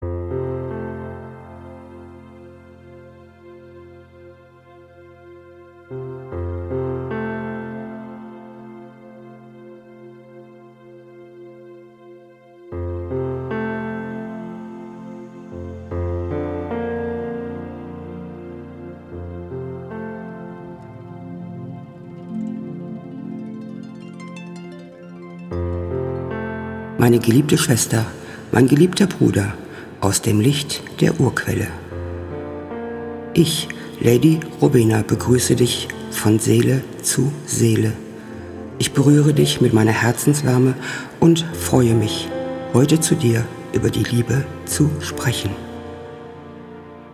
Diese Meditationen, untermalt mit traumhaft schöner Musik, können dich ganz tief zu deinem wahren Selbst führen.